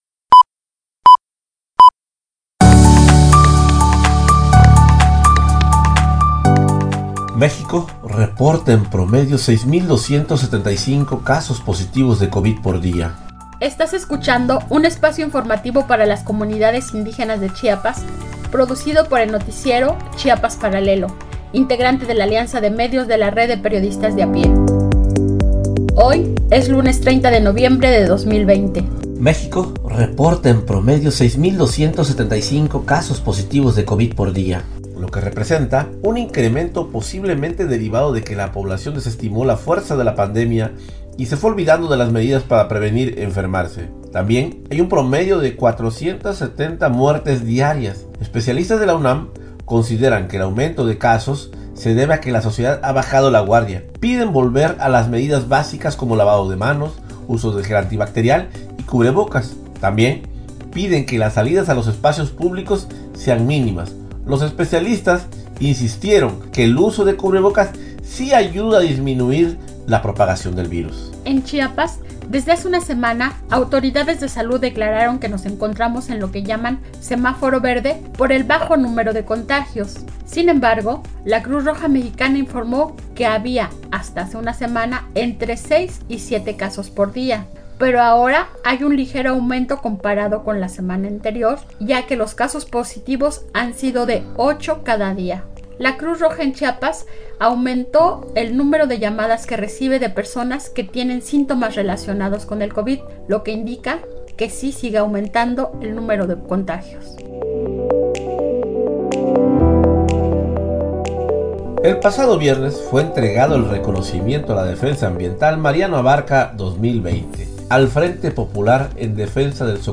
Resumen informativo COVID-19